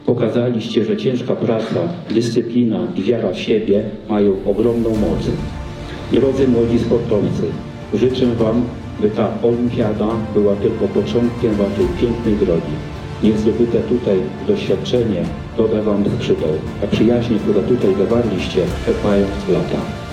Na zamku w Tykocinie zakończyła się XXXI Ogólnopolska Olimpiada Młodych, Podlasie 2025.
Zawodnikom oraz trenerom za zaangażowanie dziękował, podczas uroczystości zamknięcia olimpiady, wicemarszałek województwa podlaskiego Marek Malinowski.